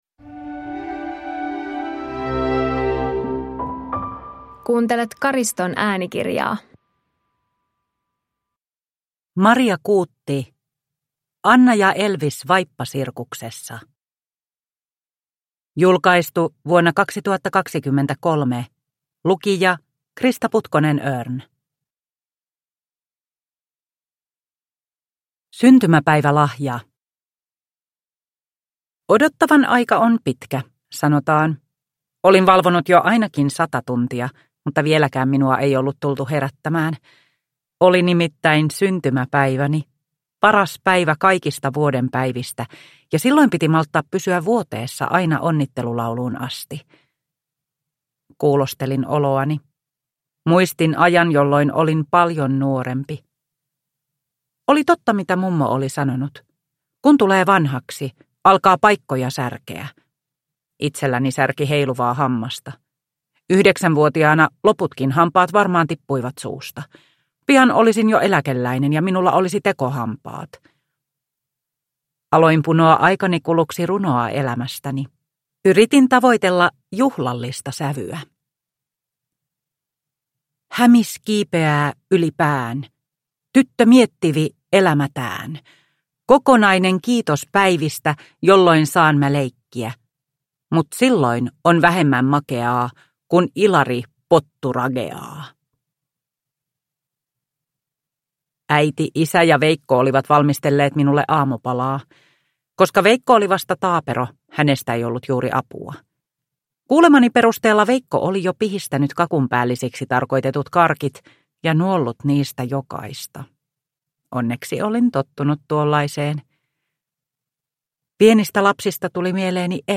Anna ja Elvis vaippasirkuksessa – Ljudbok – Laddas ner